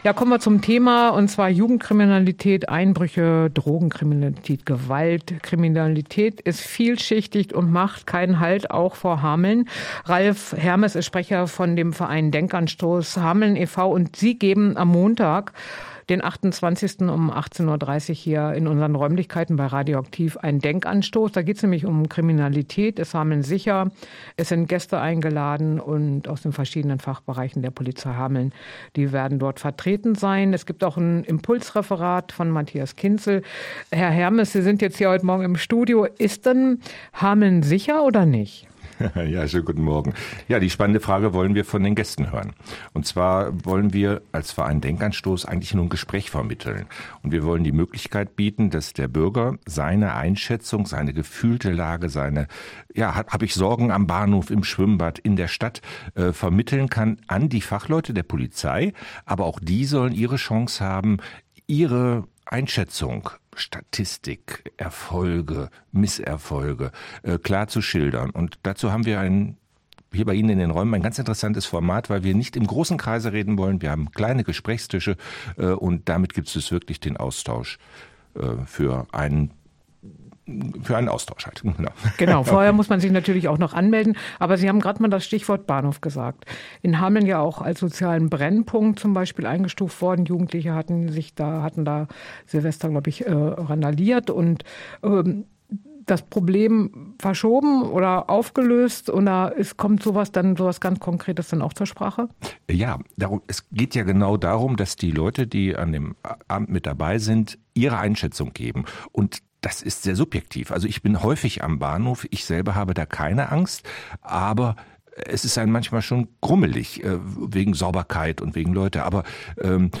Hameln: Gesprächsrunde „Ist Hameln sicher?“